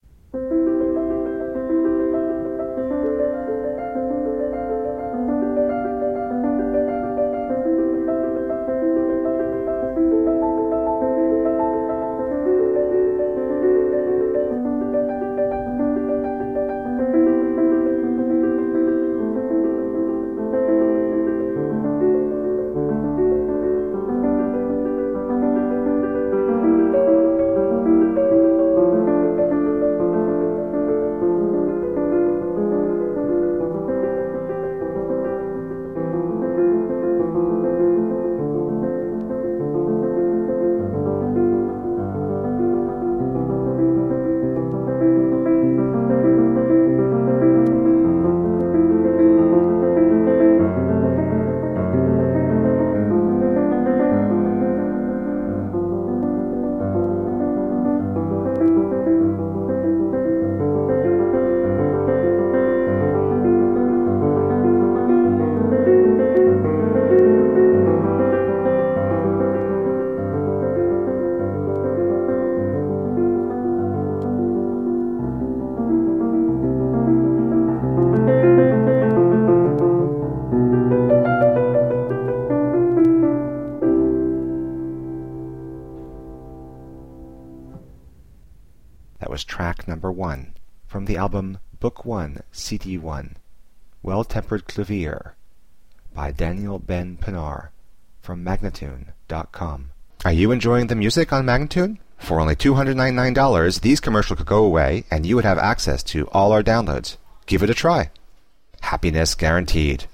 played with deep expressiveness and intelligence
solo piano music